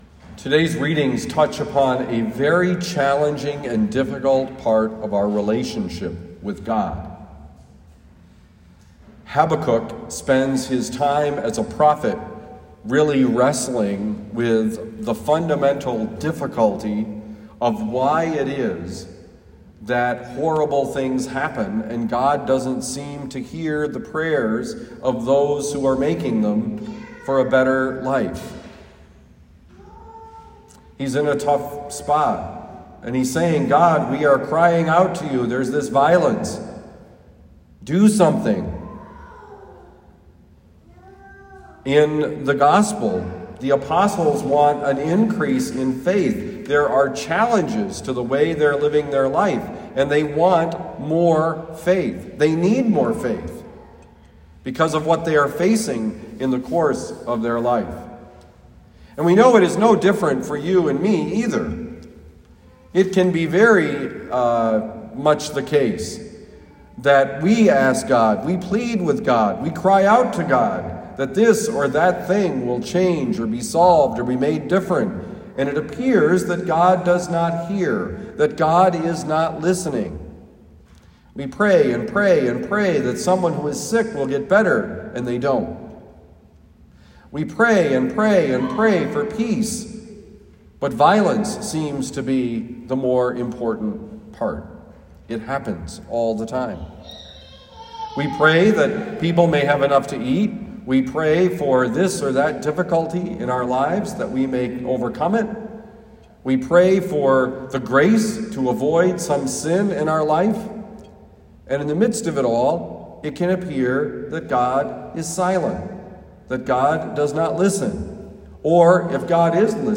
Homily given at Our Lady of Lourdes, University City, Missouri.